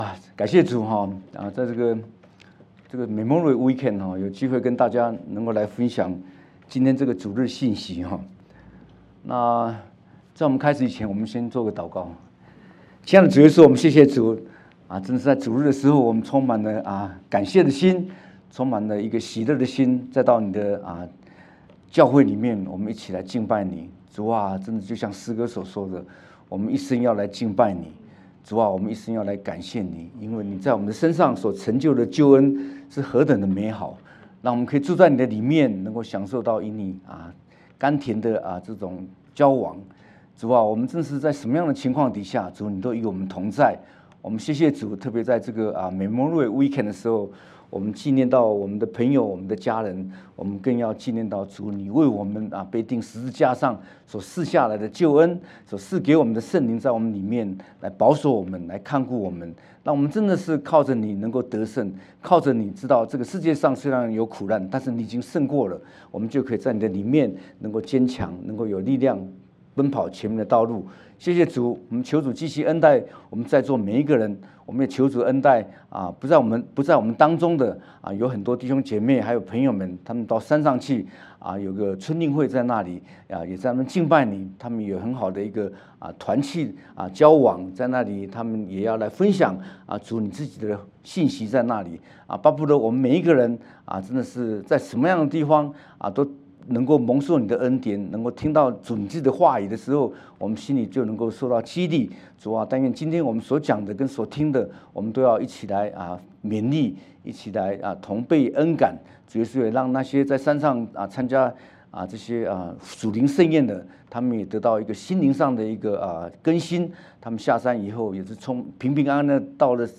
主頁 Home 關於我們 About Us 小組 Small Groups 事工 Ministry 活動 Events 主日信息 Sermons 奉獻 Give 資源 Resources 聯絡我們 Contact 使五千人吃飽的神蹟